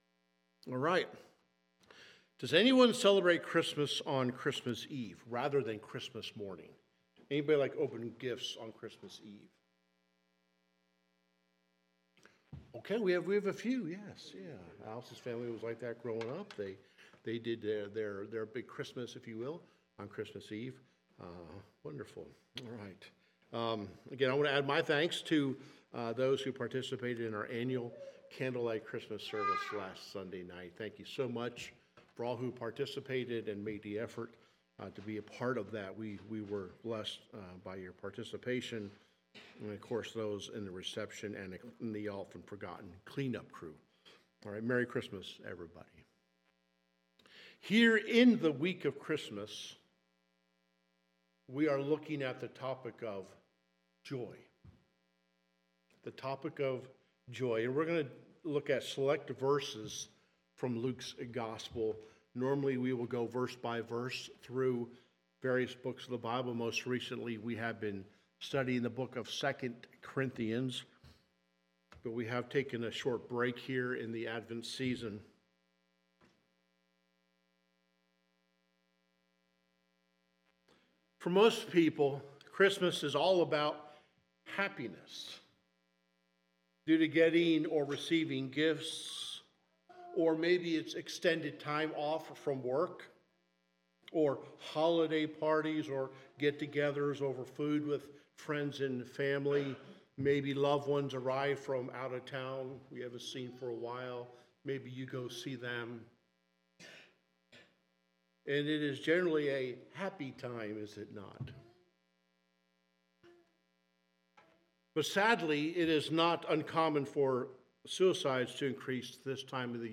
Sermons | Highland Baptist Church